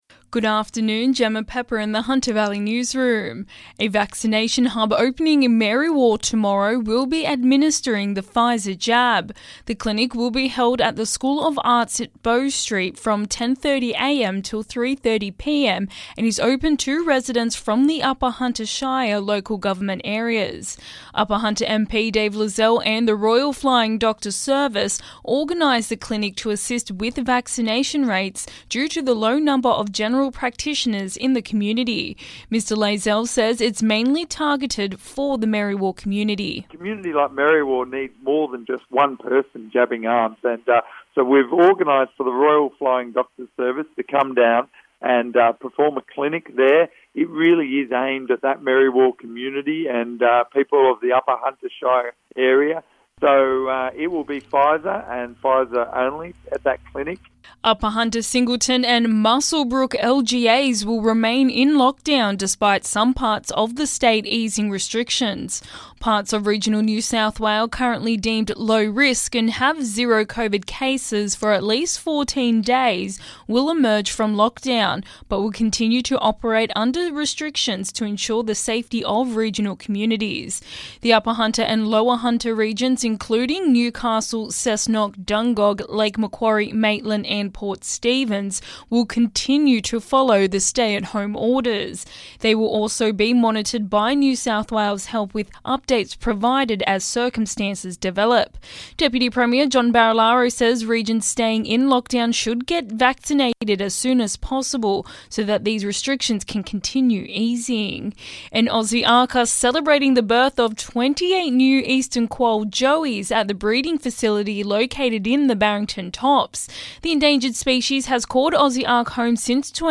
Listen: Hunter Local News Headlines 09/09/2021